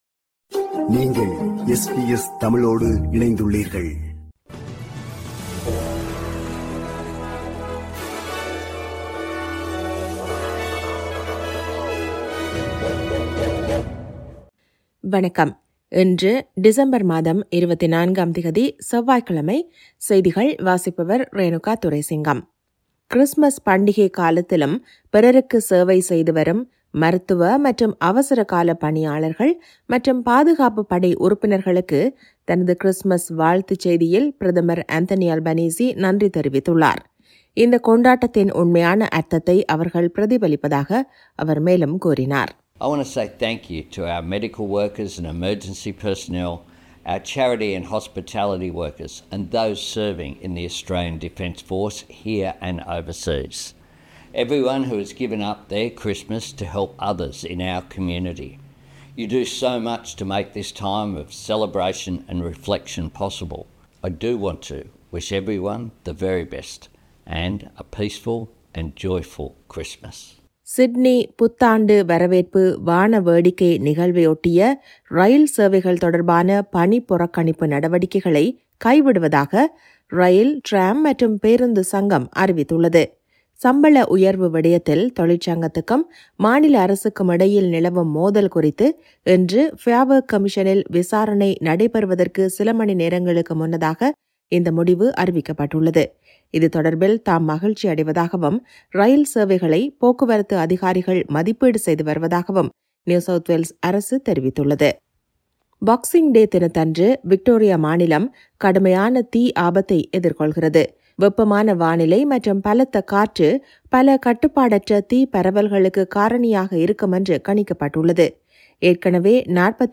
Al che noi abbiamo aperto le linee con i nostri ascoltatori e ne sono emerse le opinioni più disparate.